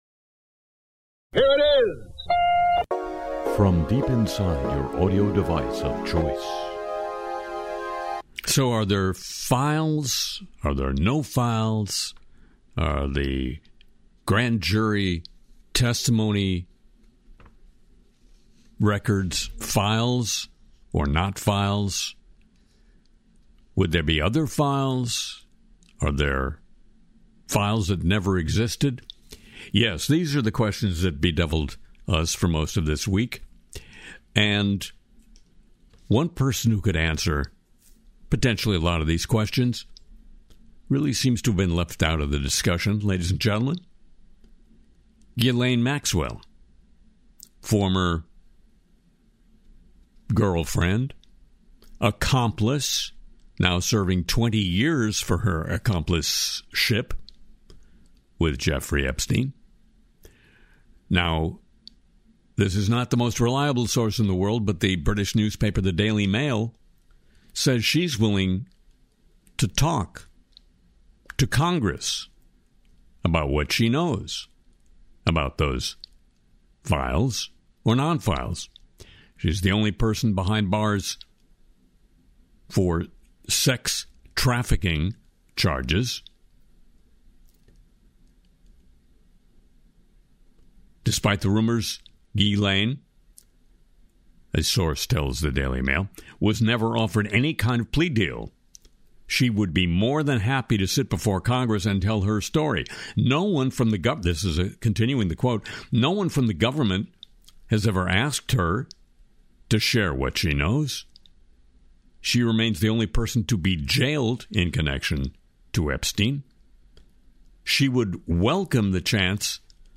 This week, Harry Shearer spoofs Trump over the Epstein Files, mocks Stephen Miller with a Steve Miller-style song, dives into ICE flight data, AI-powered nuclear reactors, and xAI safety concerns.